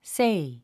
発音
séi　セェイ